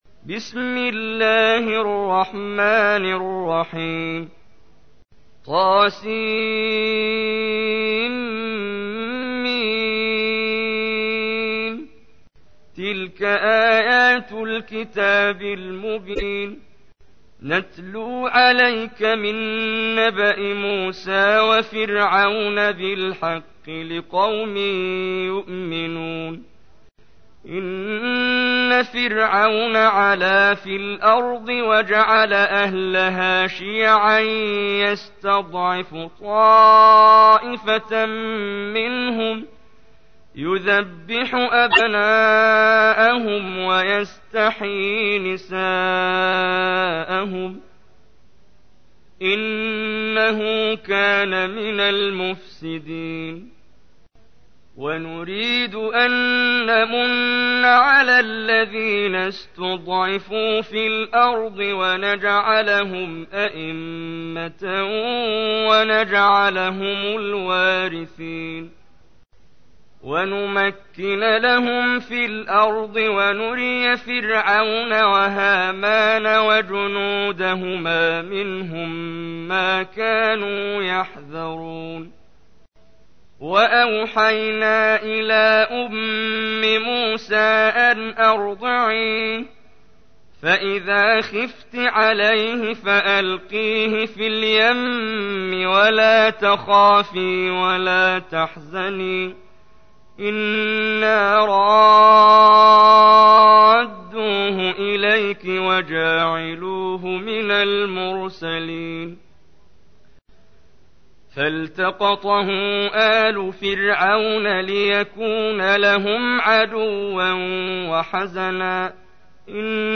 تحميل : 28. سورة القصص / القارئ محمد جبريل / القرآن الكريم / موقع يا حسين